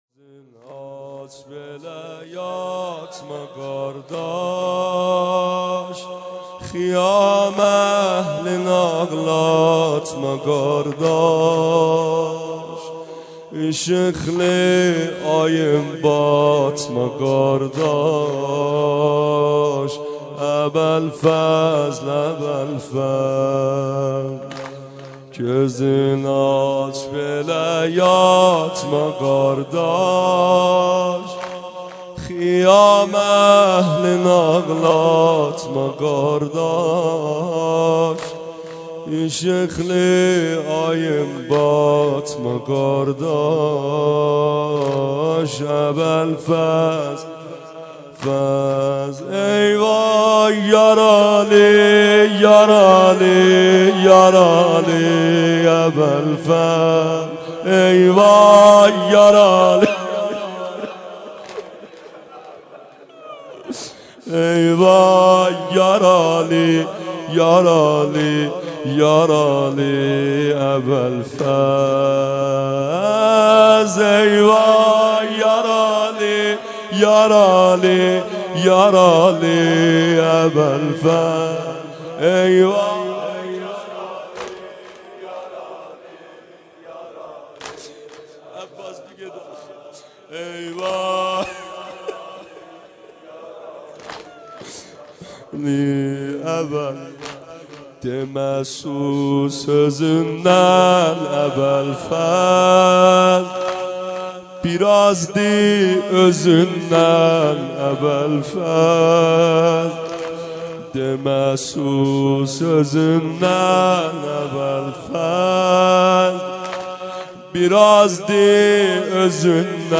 سینه زنی سنگین ترکی
شب تاسوعا 94 هیئت عاشورائیان زنجان
سینه زنی سنگین | حضرت عباس (ع)